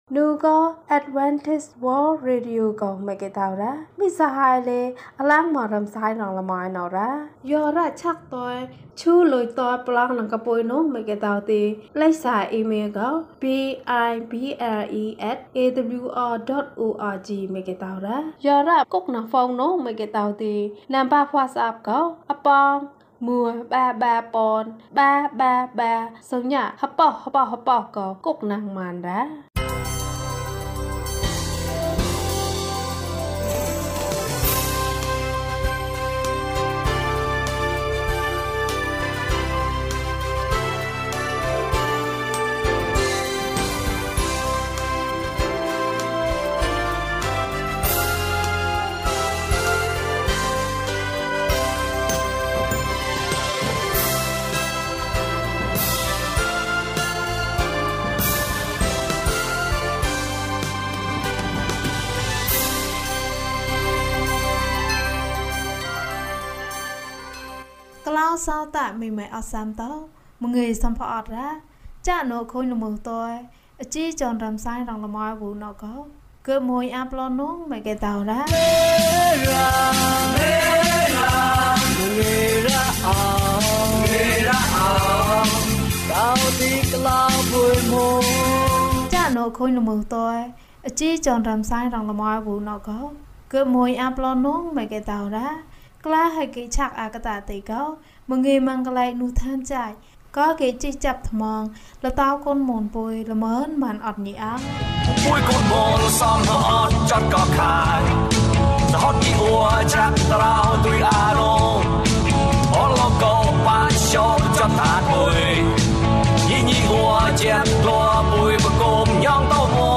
ကောင်းကြီးမင်္ဂလာ။ ကျန်းမာခြင်းအကြောင်းအရာ။ ဓမ္မသီချင်း။ တရားဒေသနာ။